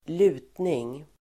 Uttal: [²l'u:tning]